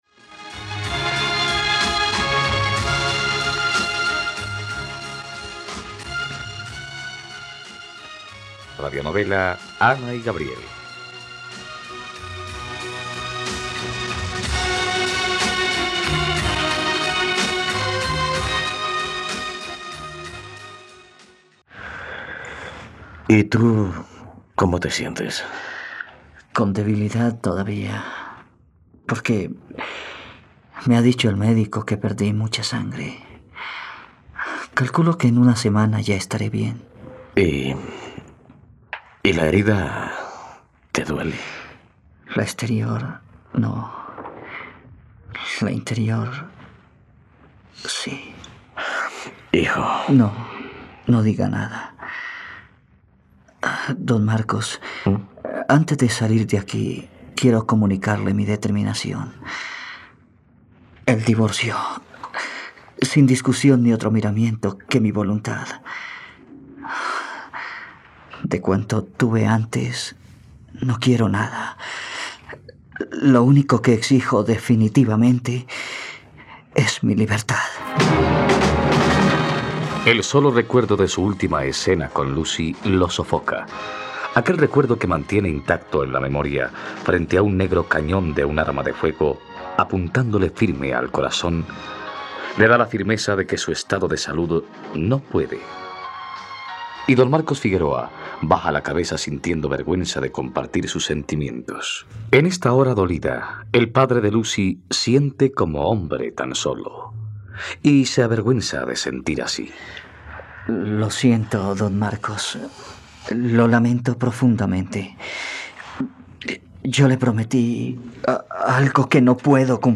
..Radionovela. Escucha ahora el capítulo 105 de la historia de amor de Ana y Gabriel en la plataforma de streaming de los colombianos: RTVCPlay.